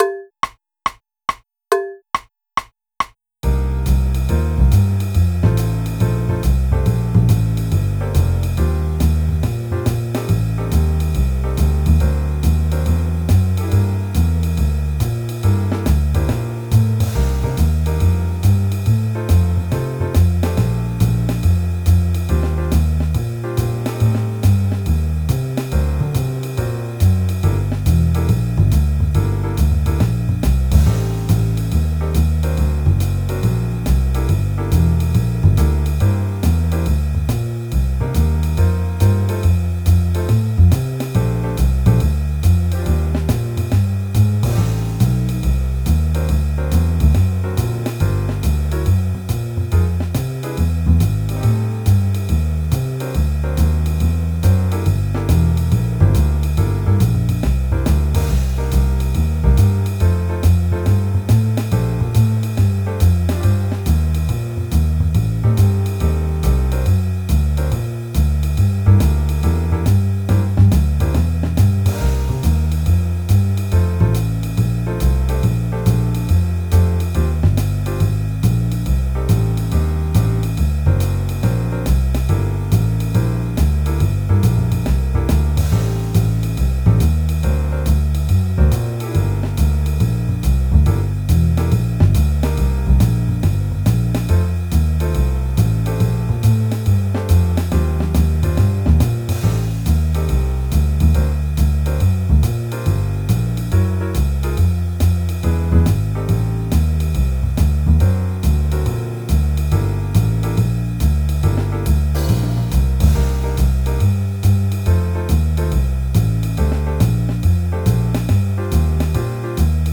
Autumn Leaves Backing Track.wav